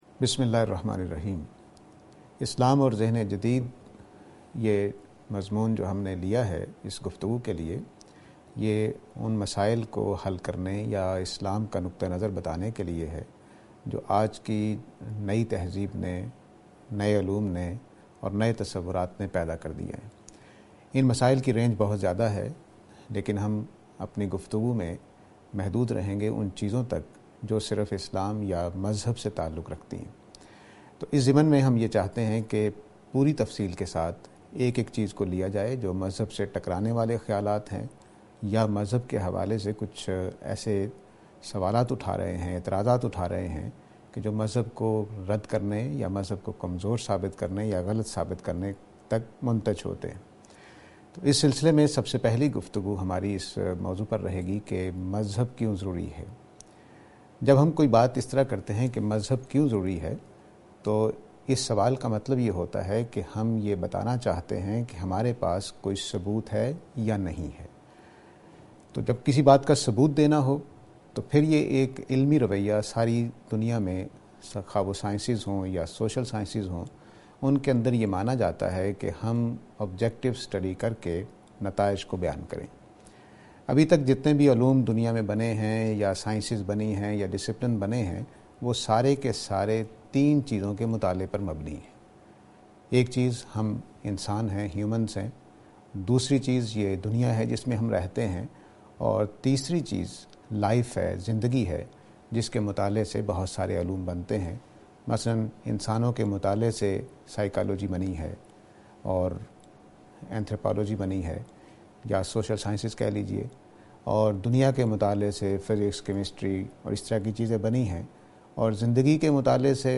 This lecture is and attempt to answer the question "Why Religion?".